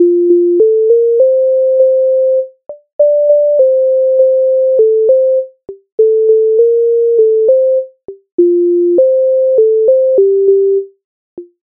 MIDI файл завантажено в тональності F-dur
Казав мені батько Українська народна пісня зі збірки Михайловської
Ukrainska_narodna_pisnia_Kazav_meni_batko.mp3